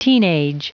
Prononciation du mot teenage en anglais (fichier audio)
Prononciation du mot : teenage